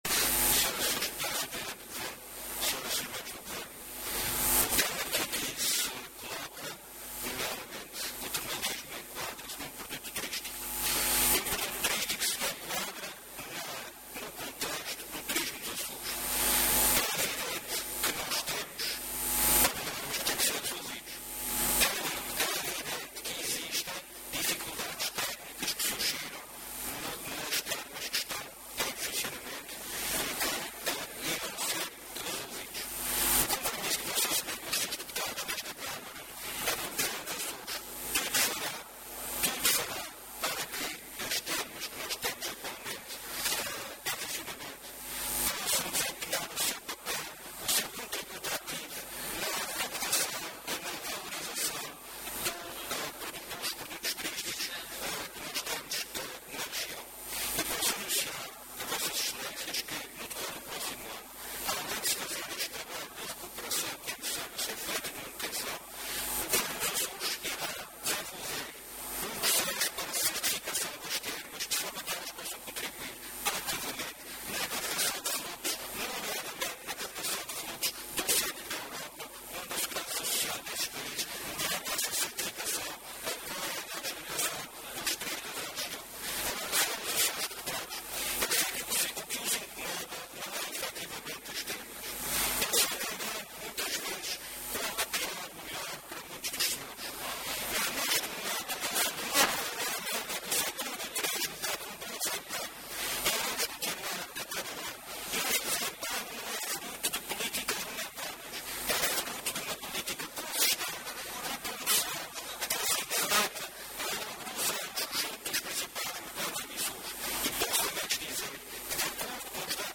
O Secretário Regional do Turismo e Transportes anunciou hoje, na Assembleia Legislativa, na Horta, que o Governo dos Açores vai avançar, já no próximo ano, com o processo de certificação das termas do arquipélago.
O Secretário Regional, que intervinha no debate que se seguiu a uma declaração política do PSD sobre o estado do termalismo nos Açores, considerou que aquilo que incomoda alguma oposição não são as termas propriamente ditas, mas o facto do setor do turismo estar “com um bom desempenho”, que considerou ser fruto de “uma política consistente de promoção que tem sido feita ao longo dos anos junto dos principais mercados emissores”.